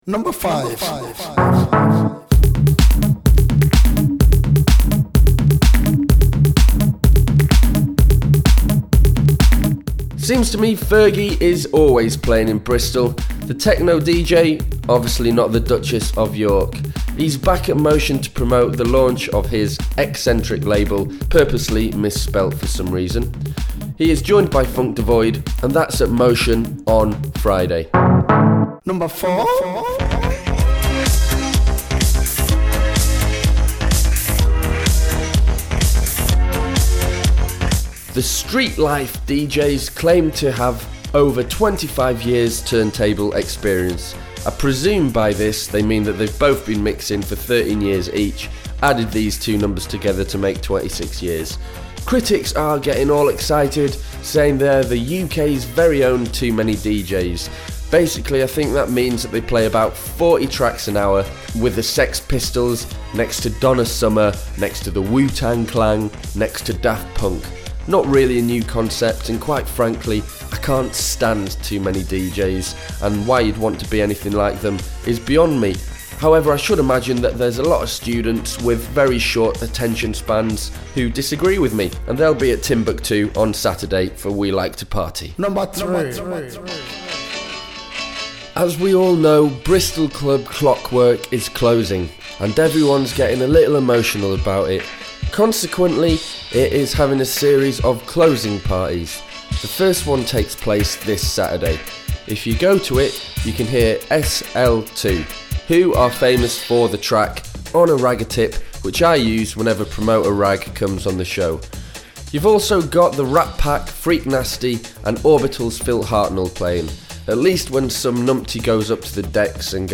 Very informative even if you don't live in Bristol...